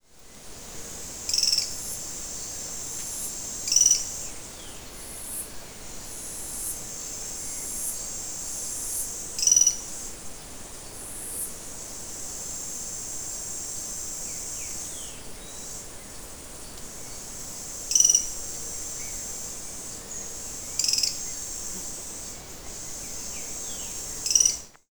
Mosqueta de Anteojos (Hemitriccus diops)
Nombre en inglés: Drab-breasted Bamboo Tyrant
Localización detallada: Reserva San Rafael (procosara)
Condición: Silvestre
Certeza: Fotografiada, Vocalización Grabada
Hemitriccus-diops.mp3